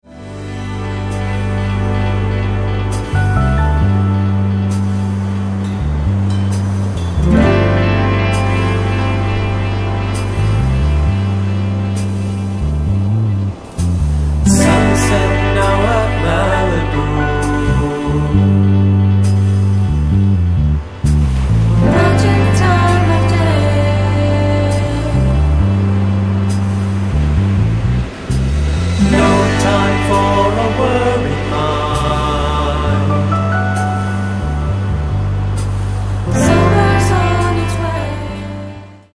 SOFT ROCK/GUITAR POP
ソフトロック、ボサにギターポップまで、全てを取り込み鳴らされる魅惑のポップサウンド。